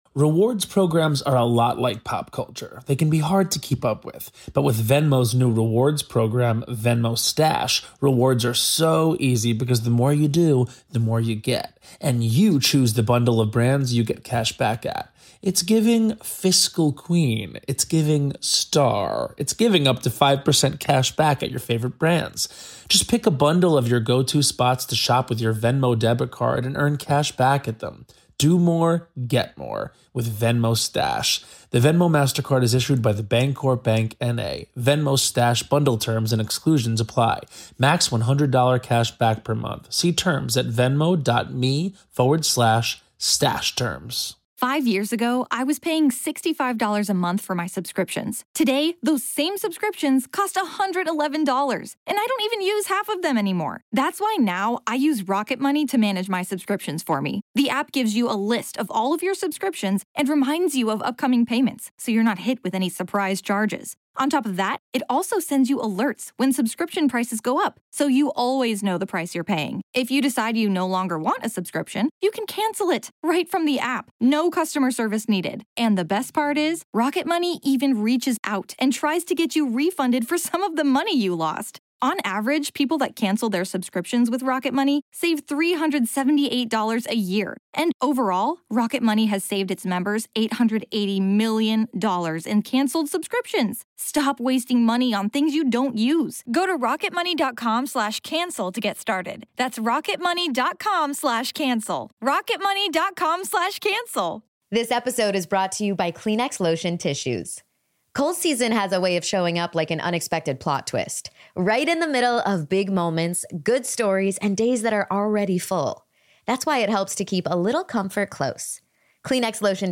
Just how much influence do demons have in our world? What avenues do dark spirits use to influence adults and children? Today we have an in-depth discussion